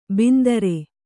♪ bindare